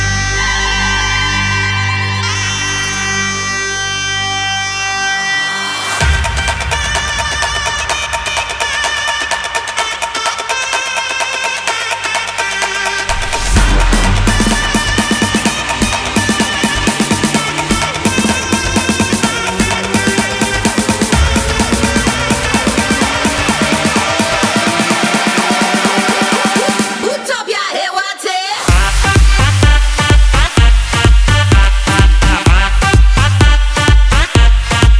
# Танцевальная